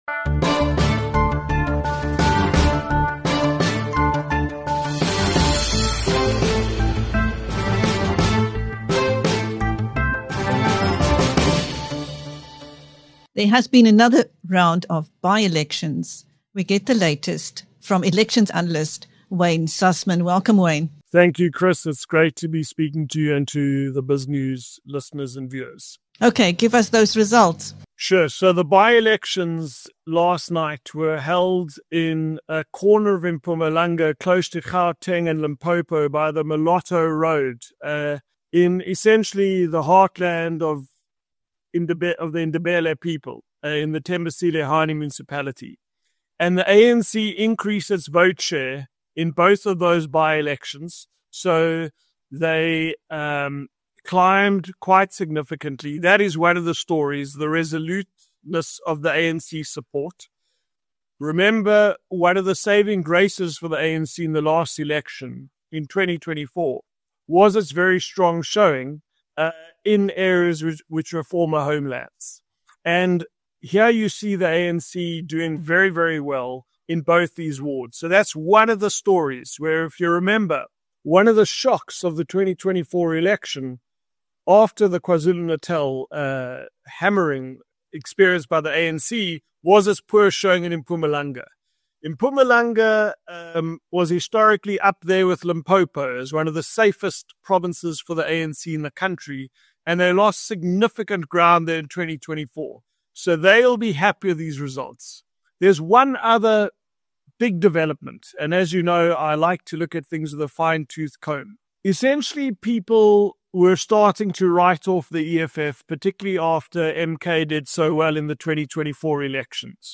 In a year of by-election results since the national election, the Patriotic Alliance (PA) is showing strong growth. In this interview with BizNews